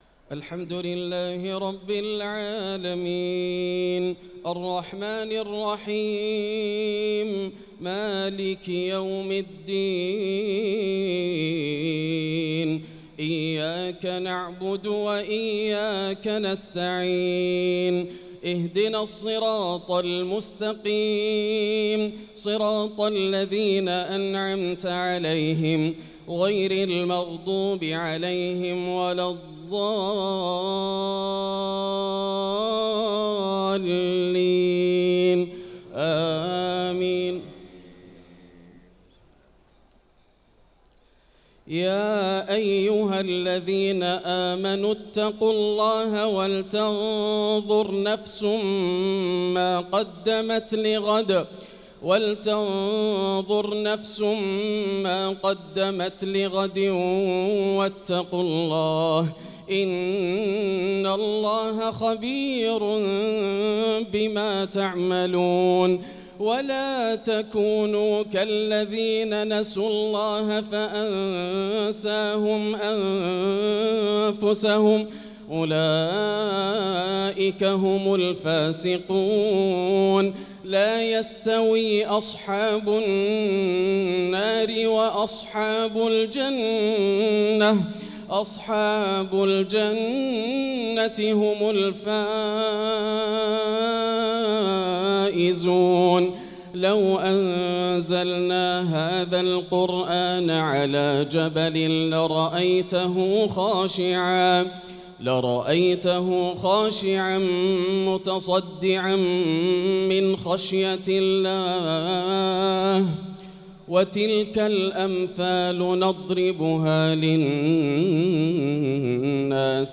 صلاة العشاء للقارئ ياسر الدوسري 26 رمضان 1445 هـ